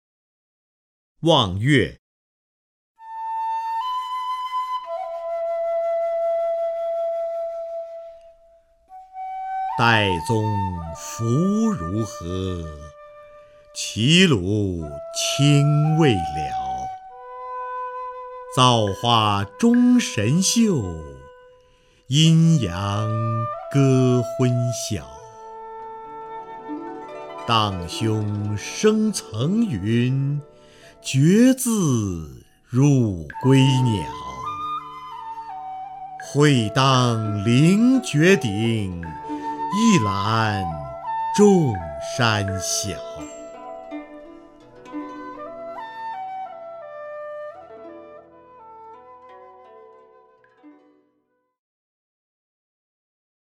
瞿弦和朗诵：《望岳》(（唐）杜甫) （唐）杜甫 名家朗诵欣赏瞿弦和 语文PLUS